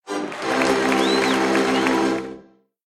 delighted_06.ogg